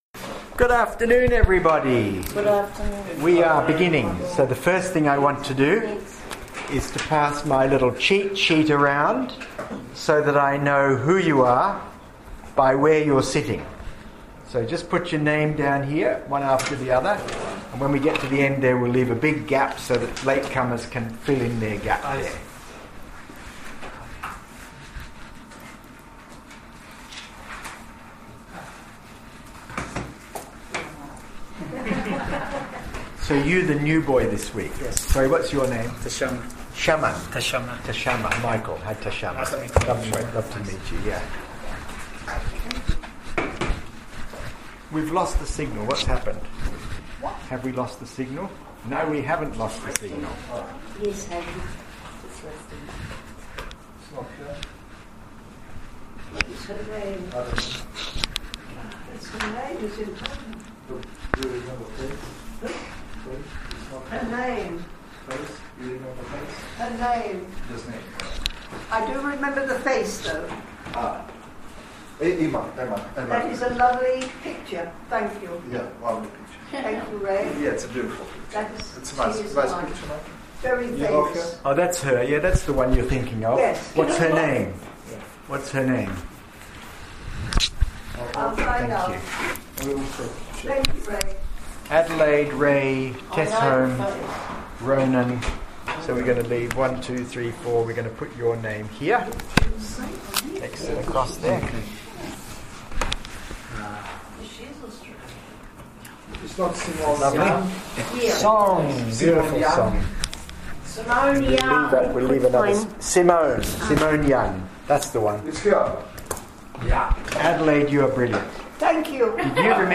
Here is the recording for this day’s lecture/ seminar: thank you all for your wonderfully creative participation!